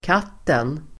Ladda ner uttalet
Uttal: [²k'at:en]